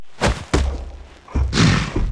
charge_attack_start.wav